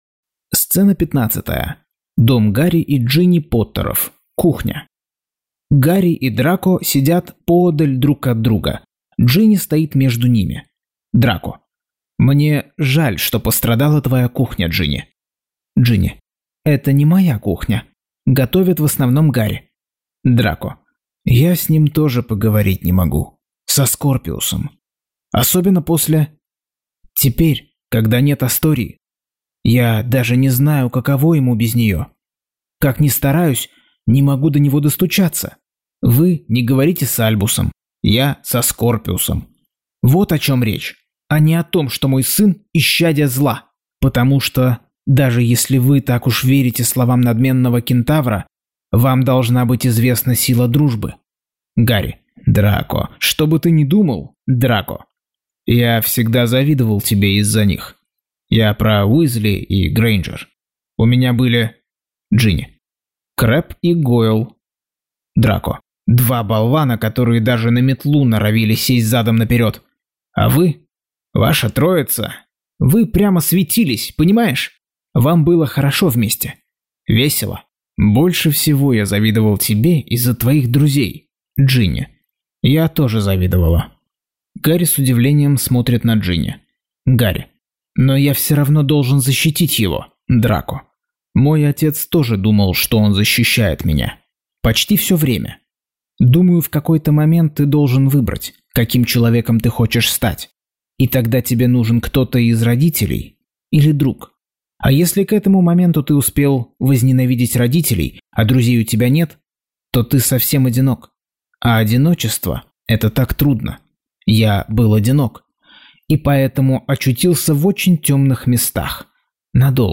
Аудиокнига Гарри Поттер и проклятое дитя. Часть 27.